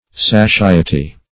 saciety - definition of saciety - synonyms, pronunciation, spelling from Free Dictionary Search Result for " saciety" : The Collaborative International Dictionary of English v.0.48: Saciety \Sa*ci"e*ty\, n. Satiety.